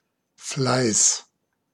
Ääntäminen
Vaihtoehtoiset kirjoitusmuodot Fleiss Ääntäminen Tuntematon aksentti: IPA: /flaɪ̯s/ Haettu sana löytyi näillä lähdekielillä: saksa Käännös Ääninäyte Substantiivit 1. diligence 2. effort GenAm US Artikkeli: der .